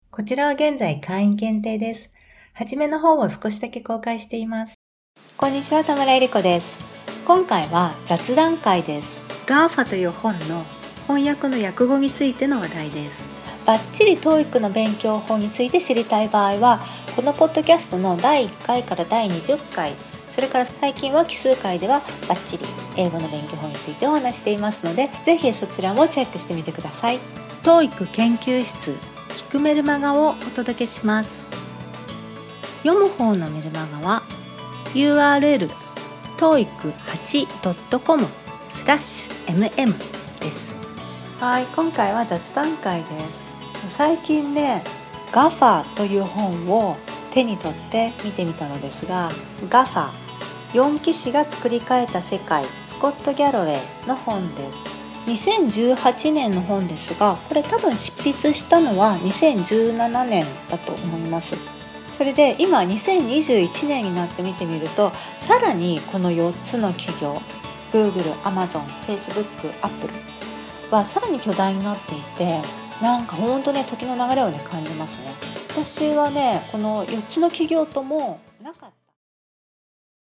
今回は雑談会です。